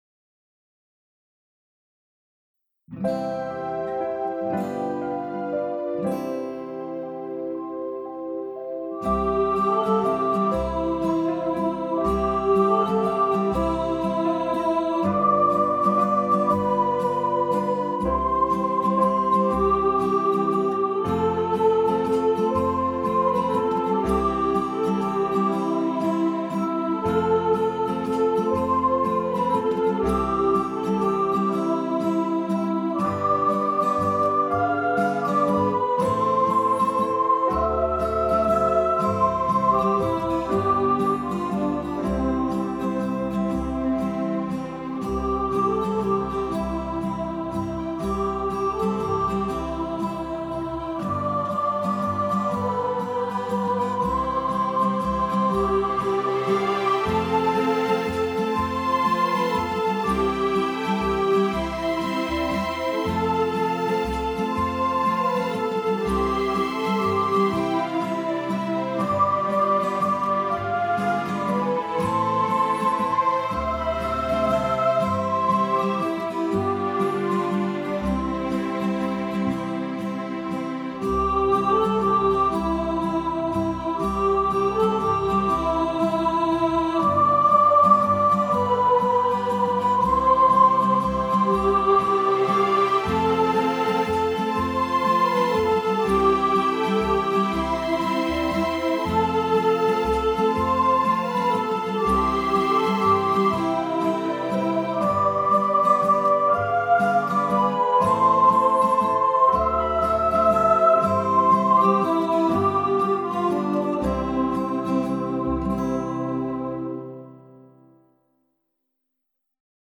Silent-Night-Soprano.mp3